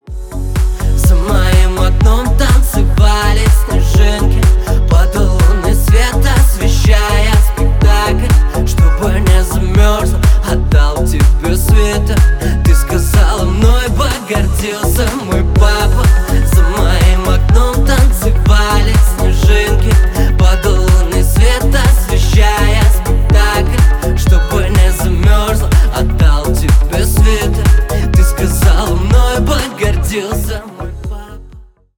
• Качество: 320 kbps, Stereo
Поп Музыка
грустные
спокойные